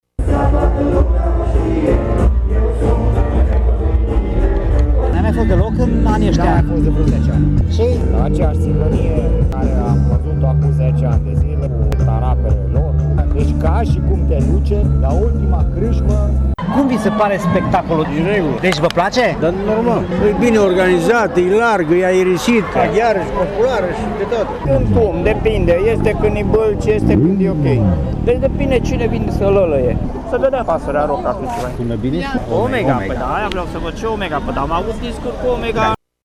Opiniile celor prezenți la spctacole au fost împărțite: